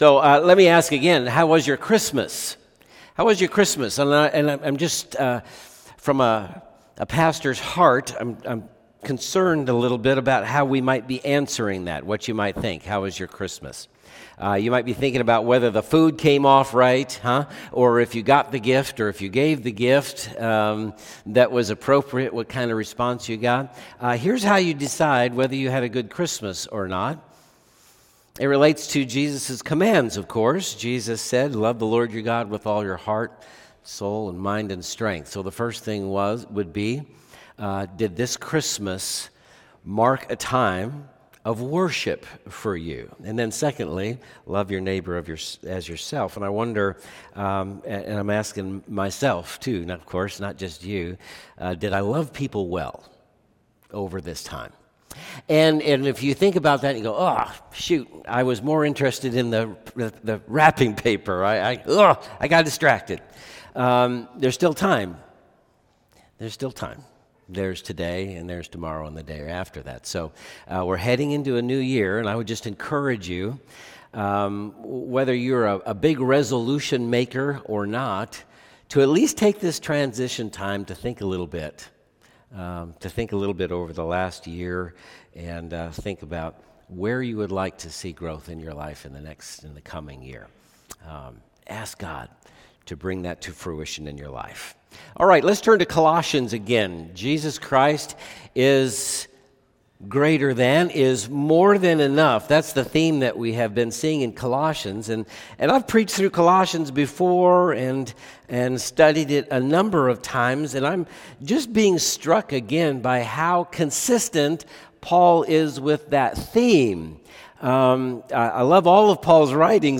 Sermons | Converge Church